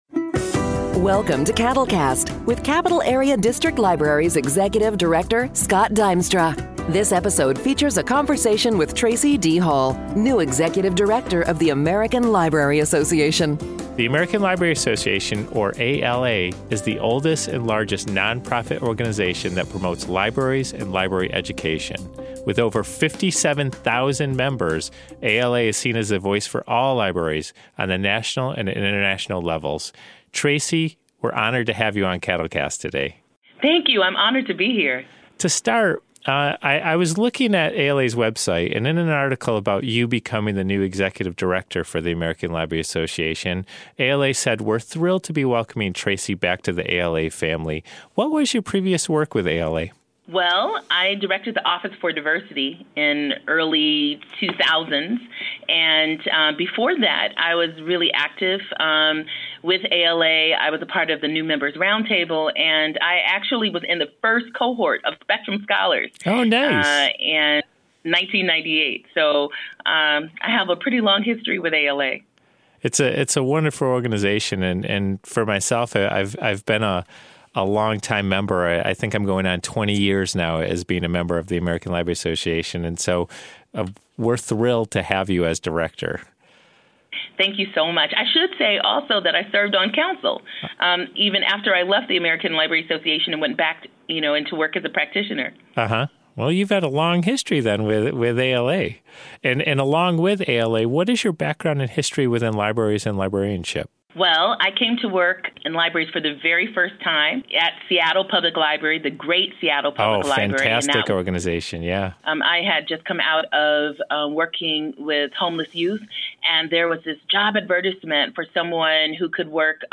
This episode features a conversation